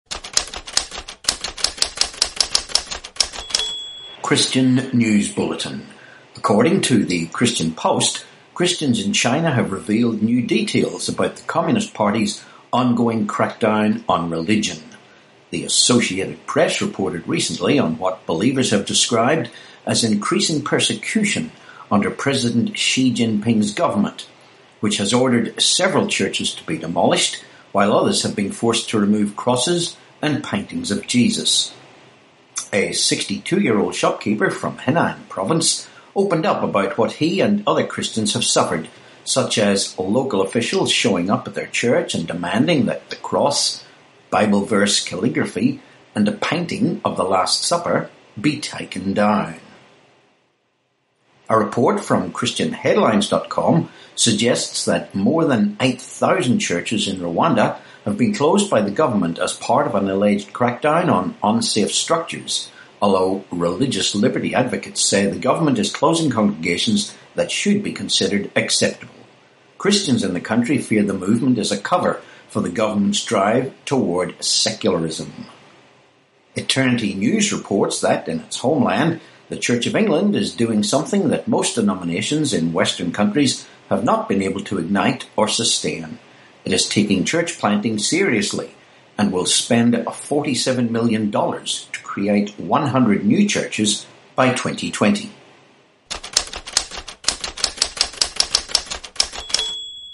19Aug18 Christian News Bulletin